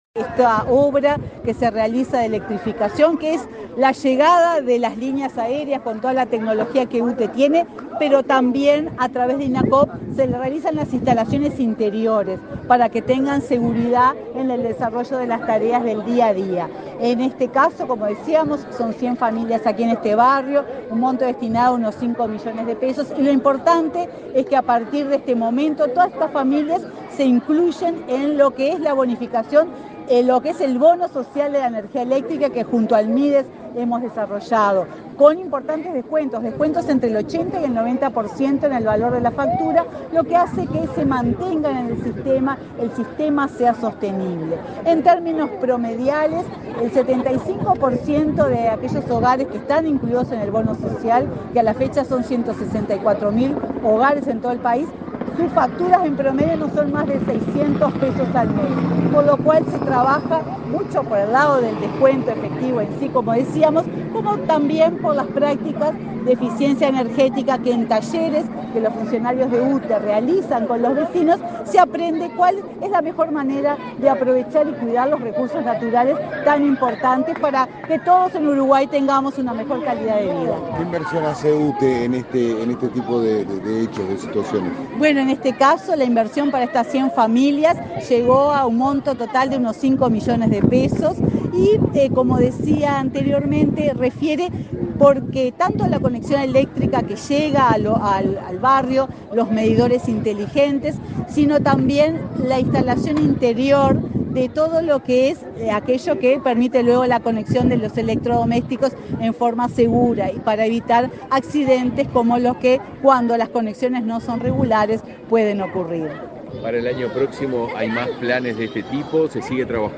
Declaraciones a la prensa de la presidenta de UTE, Silvia Emaldi
Declaraciones a la prensa de la presidenta de UTE, Silvia Emaldi 11/12/2023 Compartir Facebook X Copiar enlace WhatsApp LinkedIn UTE inauguró, este 11 de diciembre, obras de electrificación rural en Montevideo, en el marco del Programa de Inclusión Social. En la oportunidad, la presidenta de la empresa estatal, Silvia Emaldi, realizó declaraciones a la prensa.